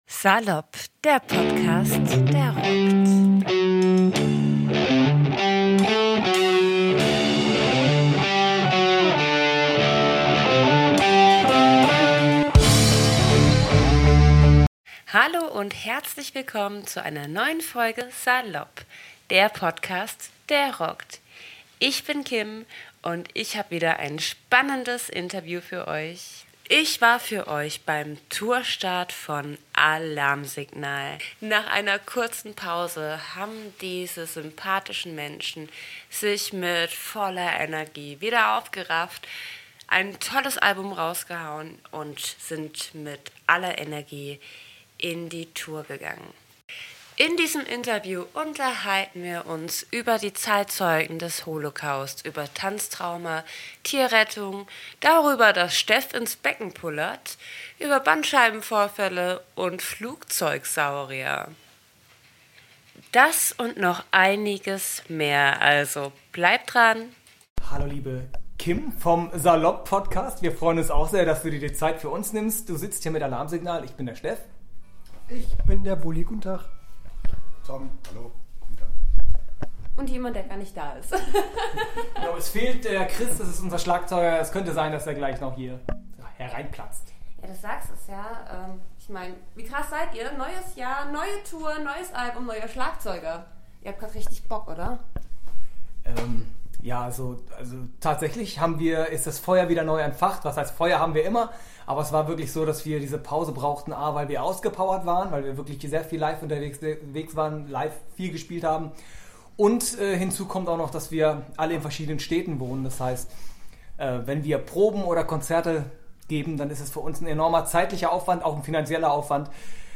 Nach einer herzlichen Begrüßung wurde ich auch schon in den Backstagebereich geführt, indem ich die Bandmitglieder von Alarmsignal ordentlich unter die Lupe ngenommen habe. Wir sprachen über die neugewonnene Energie der Band, dem neuen Album "Insomnia" und der neuen Tour.